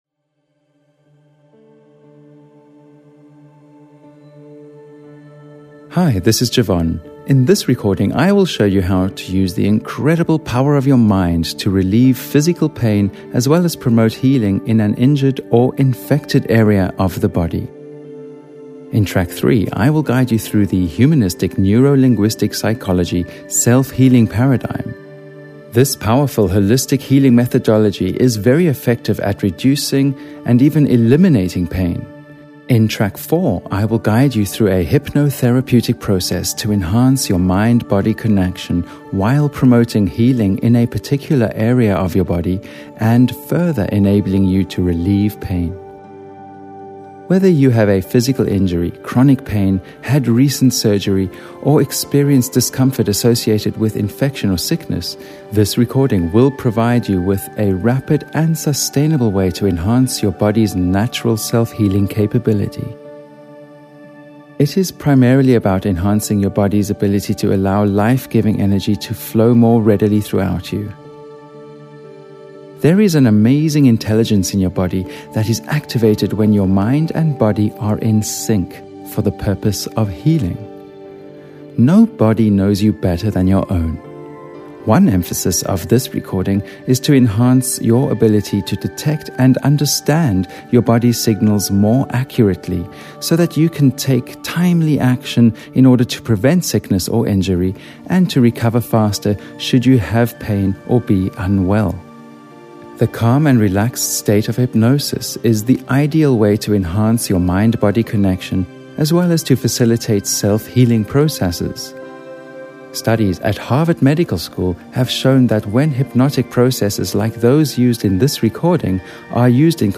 This unique Hypnotherapy recording includes the Humanistic Neuro-Linguistic Psychology Self Healing Paradigm and a guided Hypnosis session to relieve physical pain as well as promote healing in an injured or infected area of the body.
Not only is the Heal Your Body 2 recording a deeply enjoyable and relaxing hypnotherapy session, it provides your body with important healing resources and can be of great support to compliment other treatments that you may be undergoing.